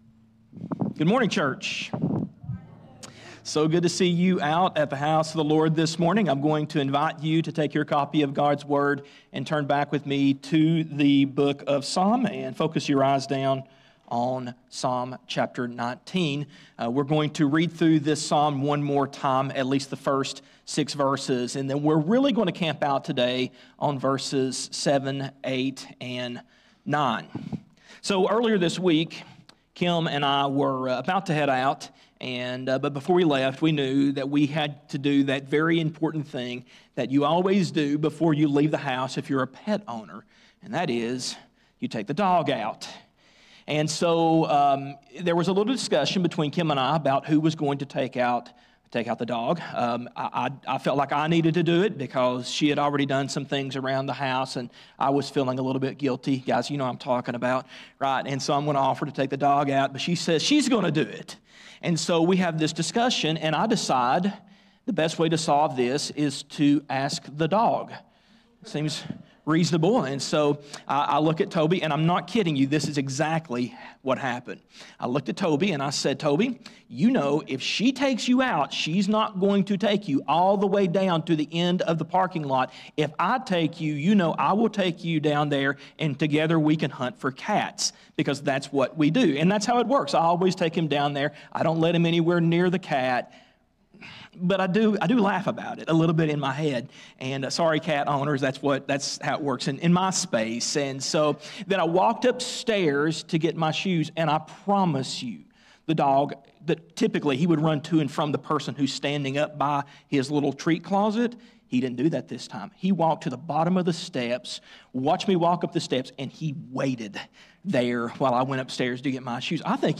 A message from the series "Faith For A Reason."